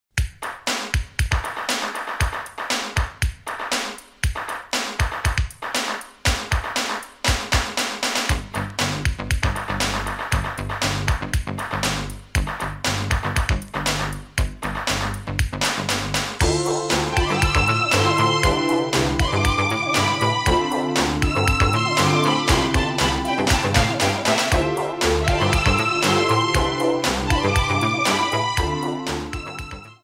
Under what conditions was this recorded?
Cut off and fade-out